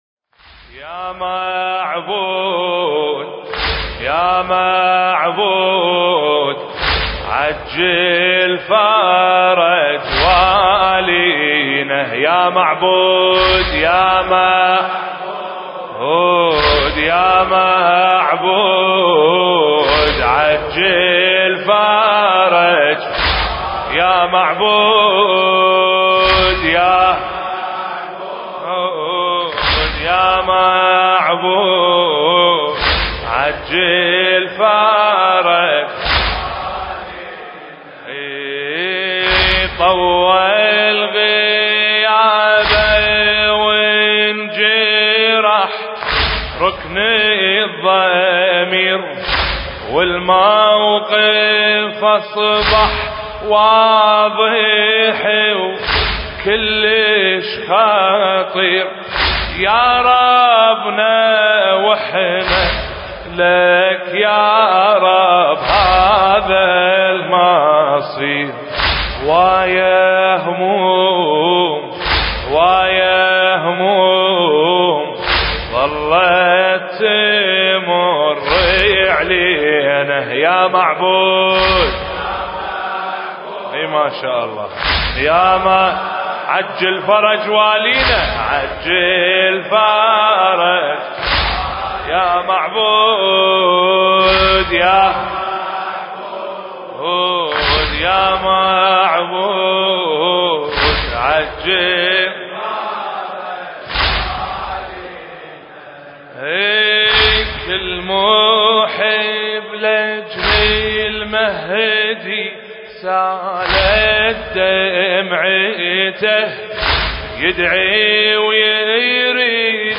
المكان: حسينية آل ياسين– الكويت